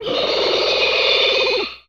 Звук кричащего черного скорпиона